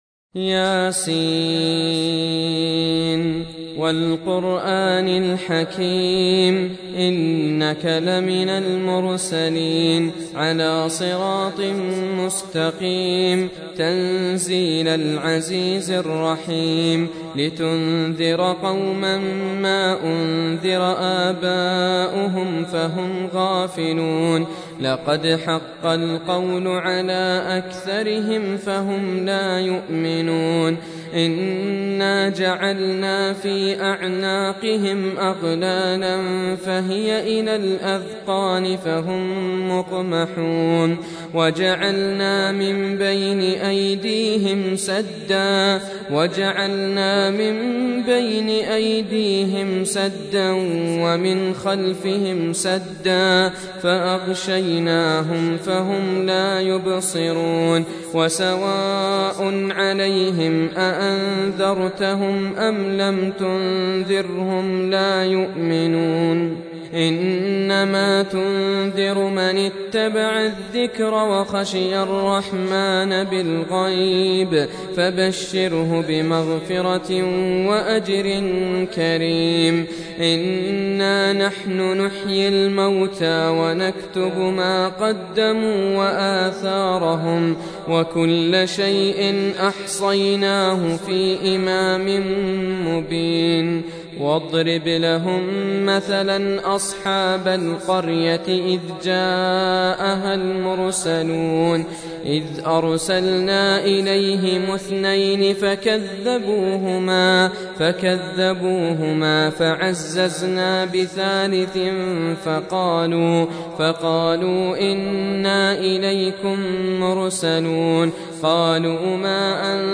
Audio Quran Tarteel Recitation
Surah Sequence تتابع السورة Download Surah حمّل السورة Reciting Murattalah Audio for 36. Surah Y�S�n. سورة يس N.B *Surah Includes Al-Basmalah Reciters Sequents تتابع التلاوات Reciters Repeats تكرار التلاوات